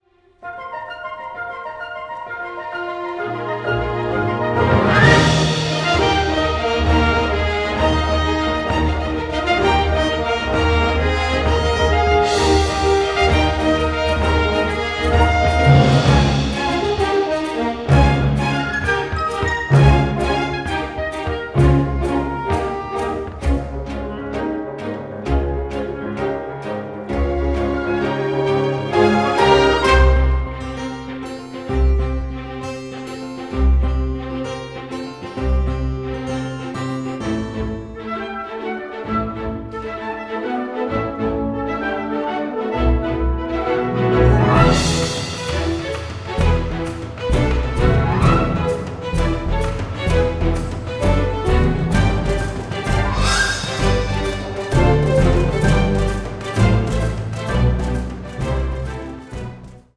delivers a poignant and deeply moving symphonic score